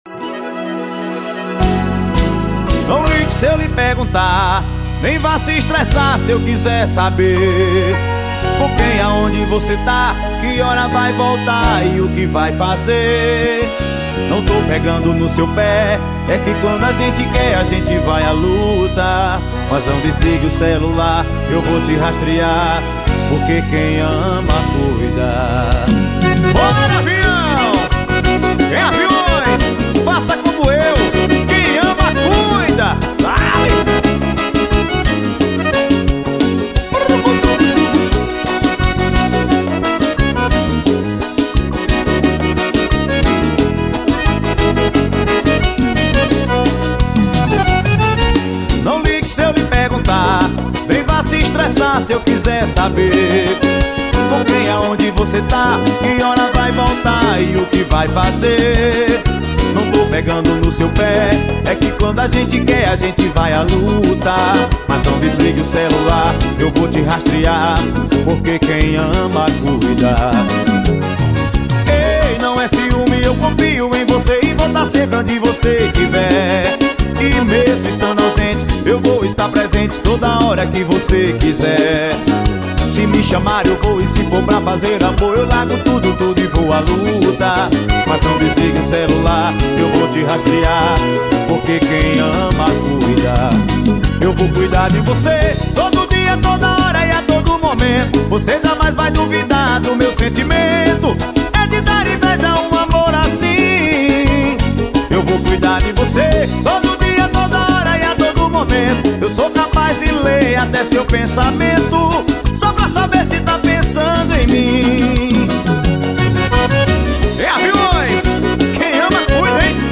Forró Views